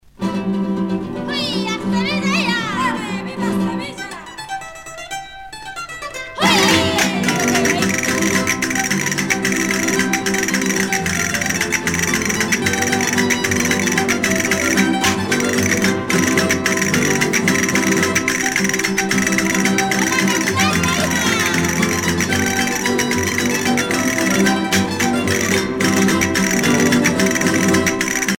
danse : flamenco
Pièce musicale éditée